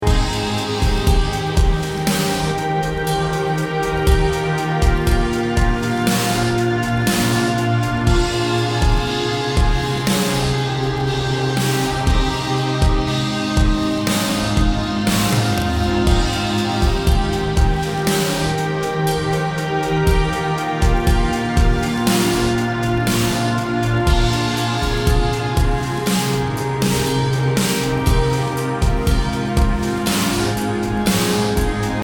528hz BPM60-69 calm Game Instrument Soundtrack インストルメント 穏やか
BPM 60